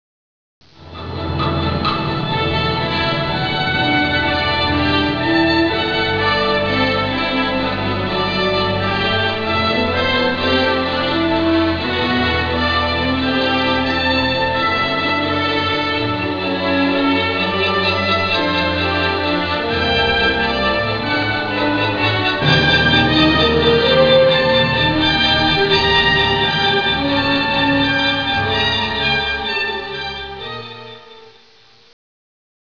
Sezione delle trombe
" una fanfara per orchestra